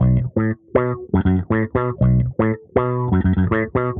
Index of /musicradar/dusty-funk-samples/Bass/120bpm